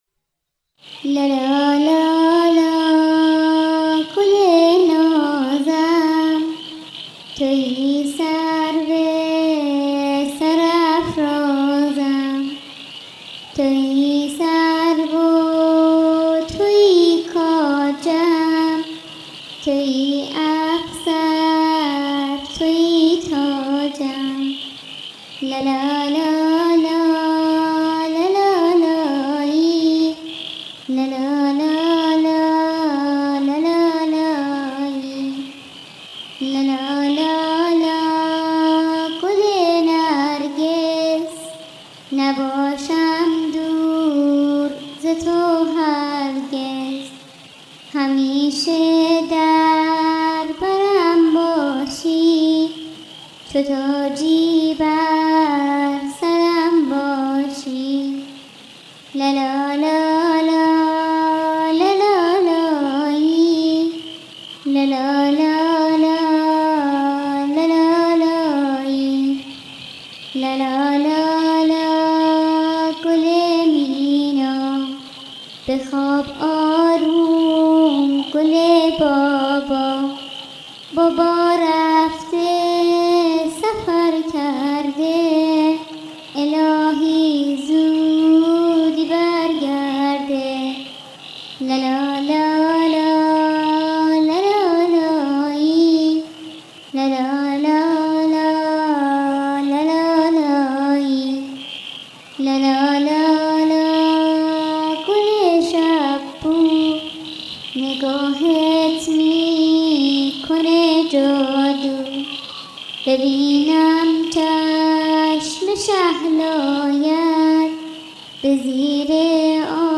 لالایی کودکانه